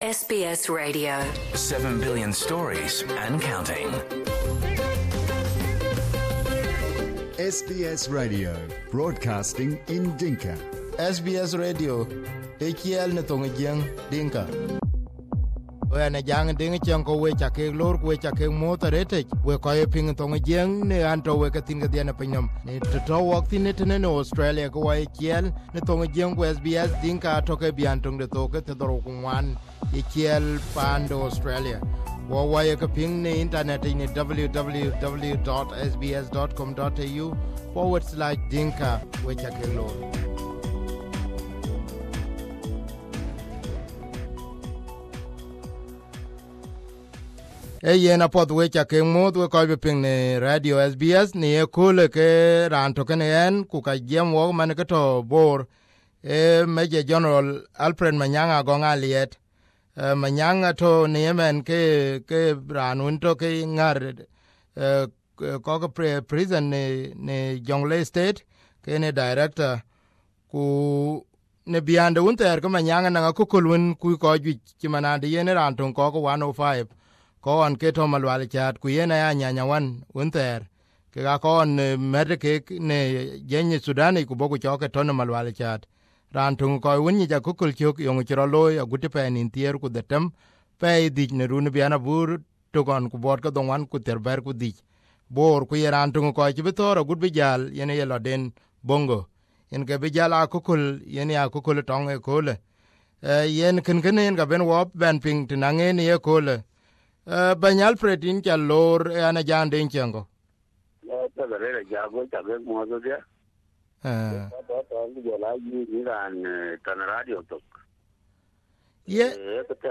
This was the first interview